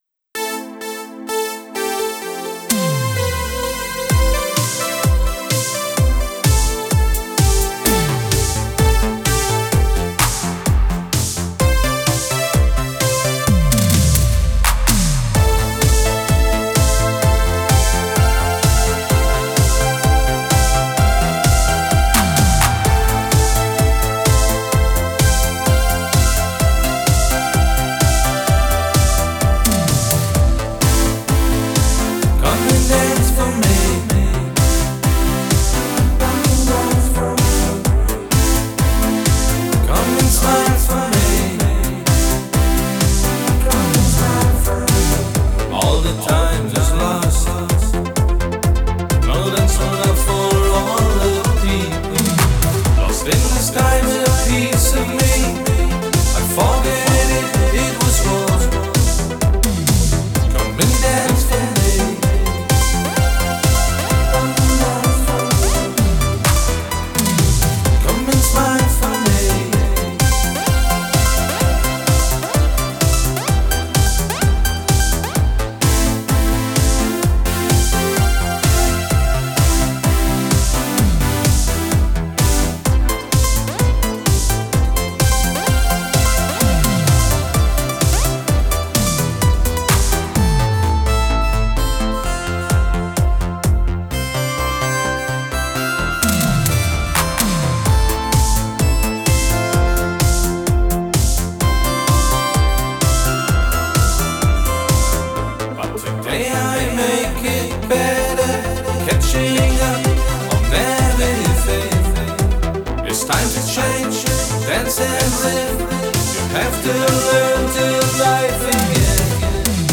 Synthpop · Synthwave · CD & Vinyl
Original Version · WAV & MP3